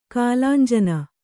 ♪ kālānjana